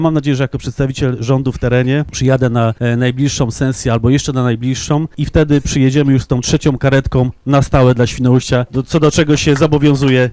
Podczas inauguracyjnej sesji rady nowego samorządu, wicewojewoda Dawid Krystek złożył obietnicę dostarczenia trzeciej karetki – długo oczekiwanej przez mieszkańców Świnoujścia.